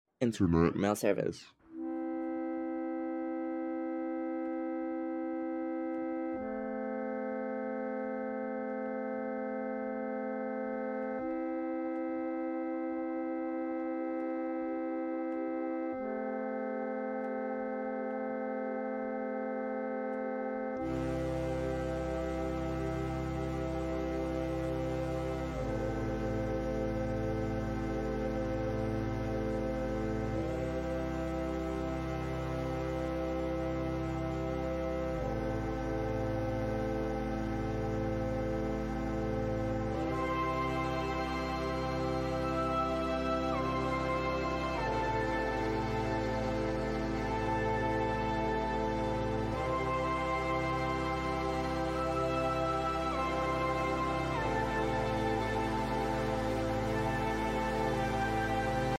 It sounds so hopeful and free.